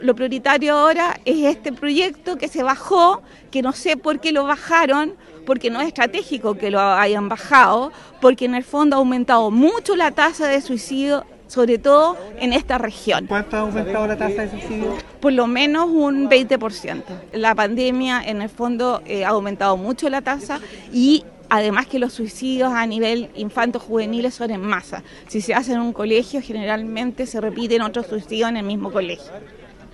Testimonios de la protesta